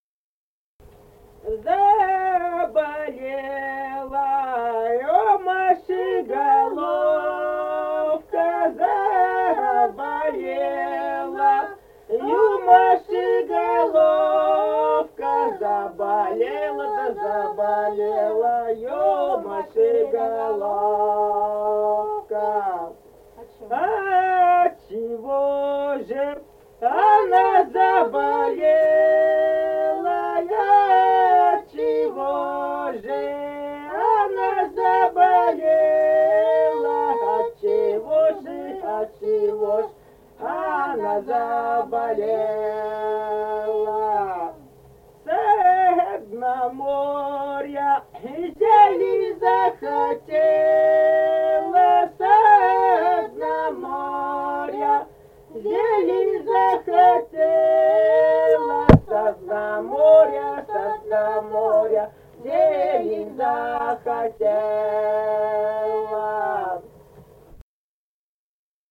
Русские песни Алтайского Беловодья 2 «Заболела у Маши головка», лирическая, пелась в компании.
Республика Казахстан, Восточно-Казахстанская обл., Катон-Карагайский р-н, с. Язовая, июль 1978.